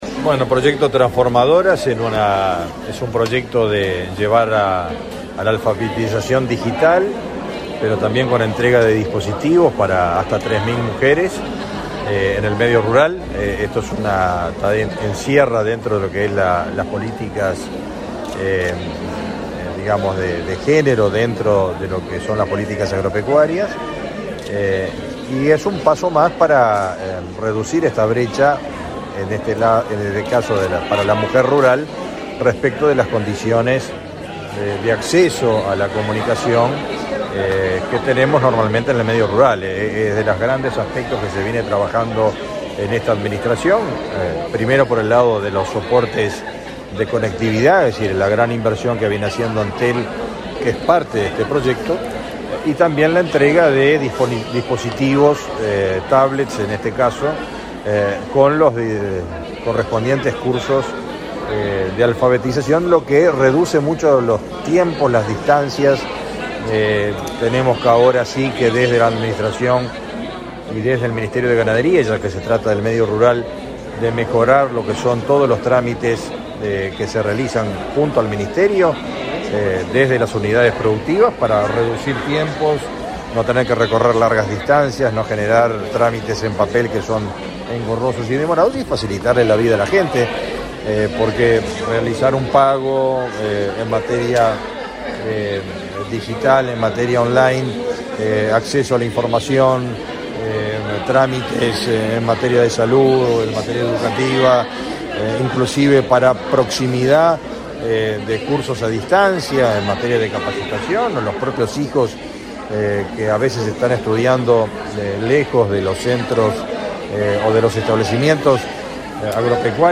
Declaraciones a la prensa del ministro de Ganadería, Fernando Mattos
Declaraciones a la prensa del ministro de Ganadería, Fernando Mattos 06/07/2023 Compartir Facebook X Copiar enlace WhatsApp LinkedIn Tras participar en la firma de un convenio entre el Ministerio de Ganadería, Agricultura y Pesca (MGAP) y el Instituto Nacional de Empleo y Formación Profesional (Inefop), este 6 de julio, el titular de la cartera, Fernando Mattos, realizó declaraciones a la prensa.